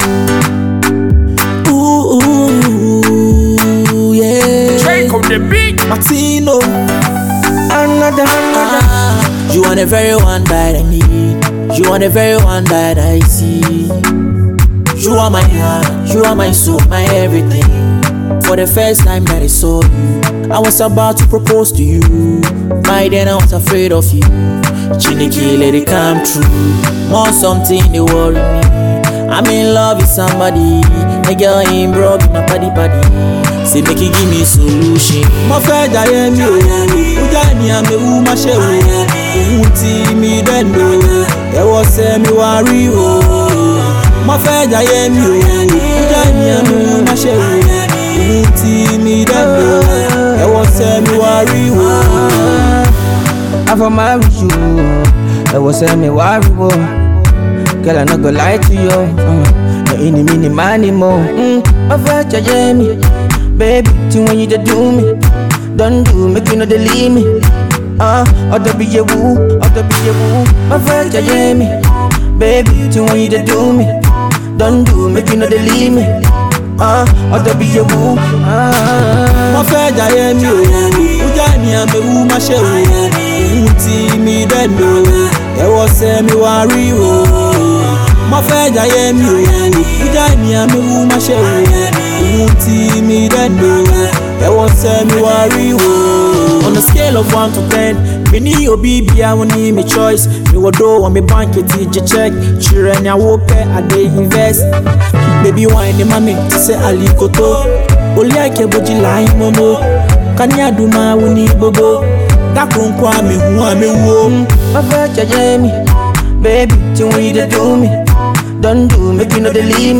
Ghana Music Music
Afrobeat